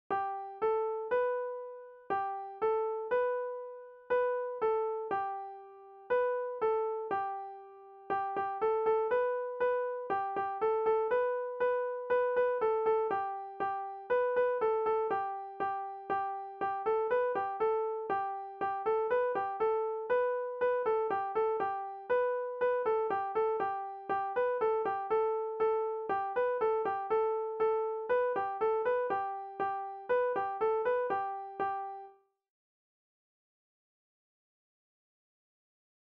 Practica o seguinte exercicio coa frauta. Escoita o audio para ter unha referencia.